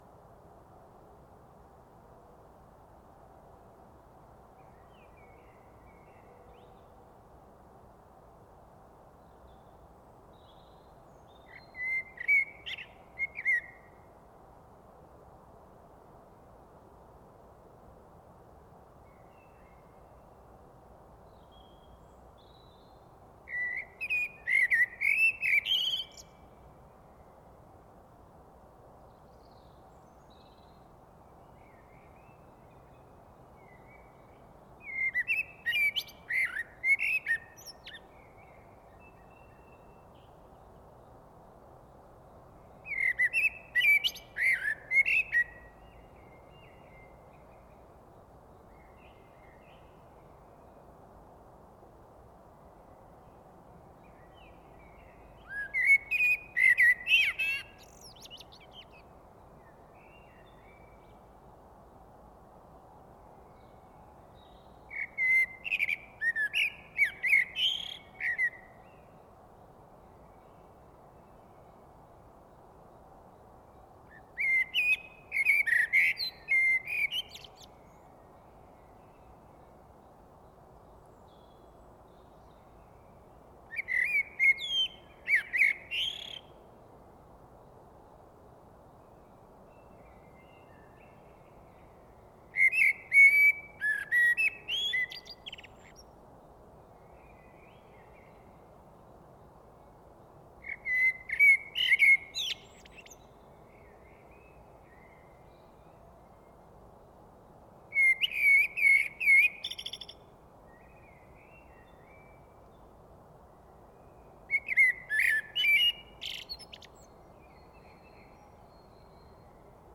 DE: Dies ist die direkte Fortsetzung der Aufnahme, die ich letzte Woche postete (aufgenommen am 4. April 2023, kurz nach 5:00 Uhr).
Wenn ihr beide Aufnahmen hintereinander hört, ist auffallend, dass Herr Amsel während des Singens immer munterer wird: Der Gesang ist zunächst zögerlich, wird aber im Verlauf der Viertelstunde kraftvoller und bestimmter.
Auch die restliche Vogelwelt erwacht nach und nach: Immer mehr Vögel stimmen in den Gesang ein.
If you listen to both recordings one after the other, it is noticeable that Mr. Blackbird becomes increasingly lively: the singing is hesitant at first, but becomes more powerful in the end.
The rest of the bird world is also gradually waking up: More and more birds join in the song.